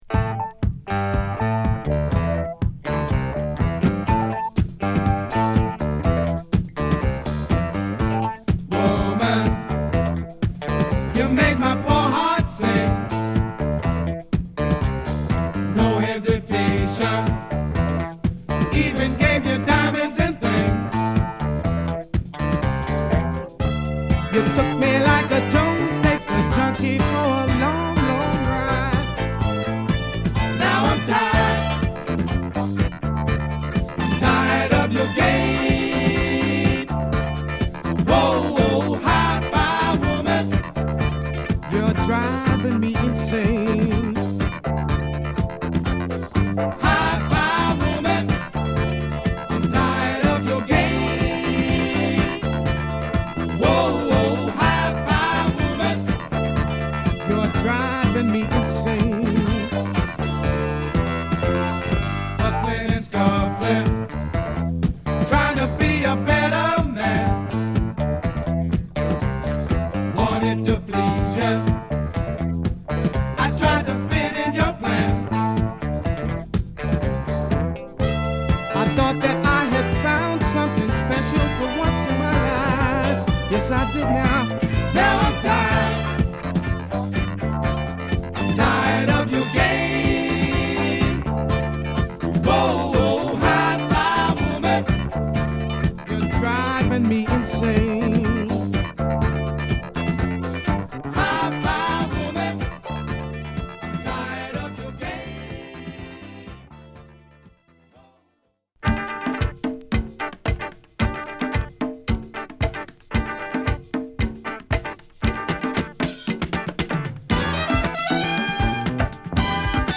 アフロ〜ファンク〜ディスコバンド
内容の方も期待を裏切らないファンキーチューンを満載！
疾走感あるA1
土着的なリズムを刻むギターにパーカッション、ソコにホーンが絡むアフロジャズファンクなA4
ファットなドラムに荒いギターが絡むファンキーなB1
＊B1からB2にかけて傷あり